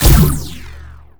sci-fi_weapon_blaster_laser_boom_02.wav